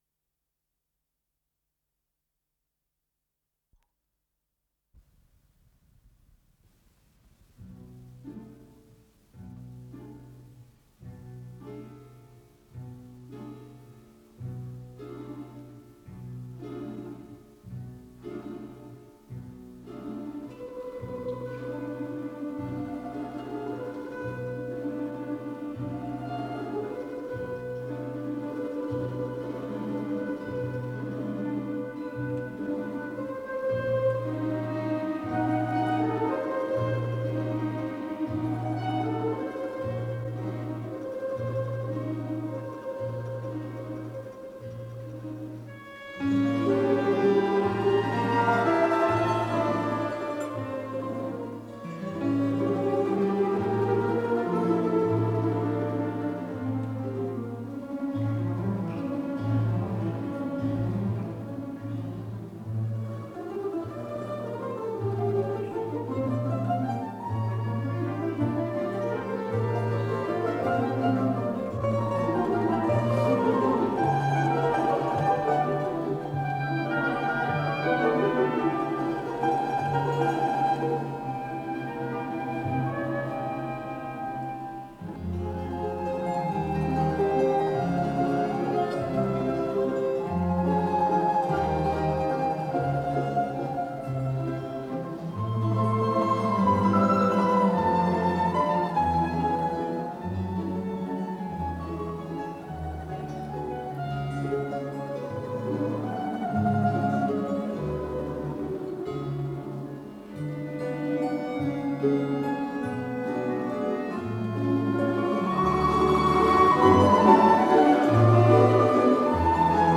с профессиональной магнитной ленты
си минор
ВариантДубль моно